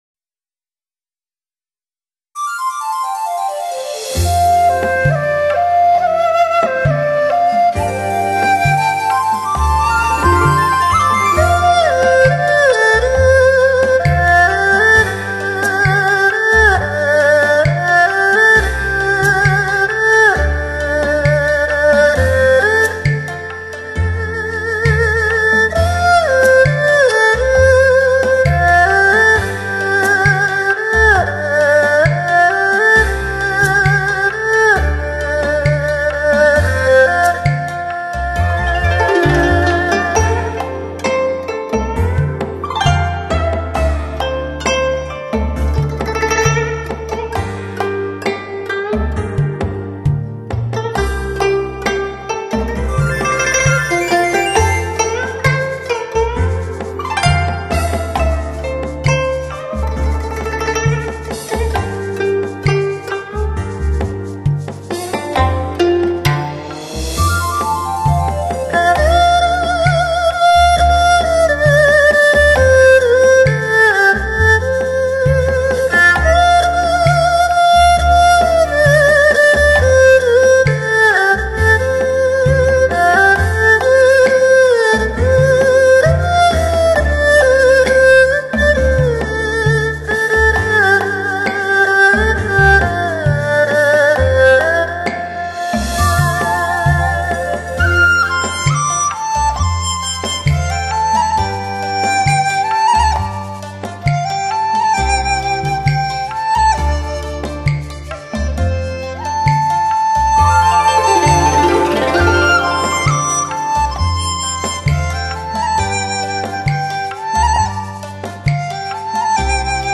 小提琴